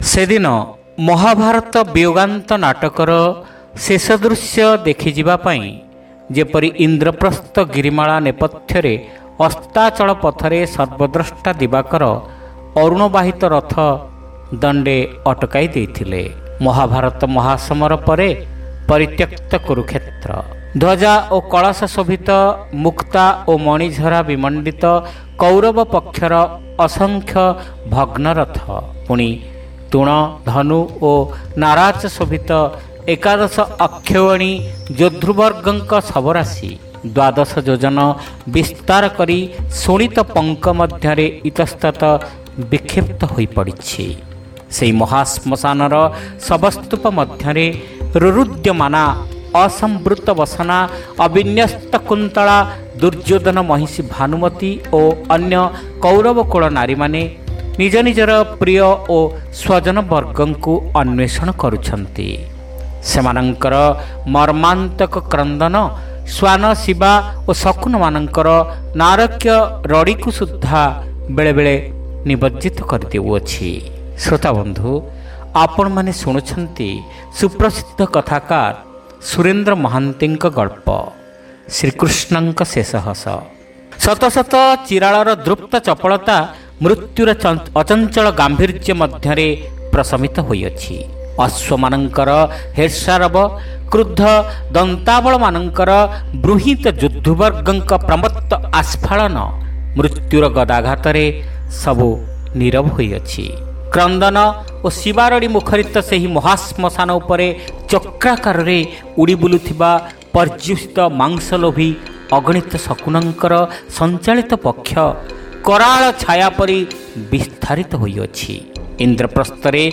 Audio Story : Srikrishna Nka Shesa Hasa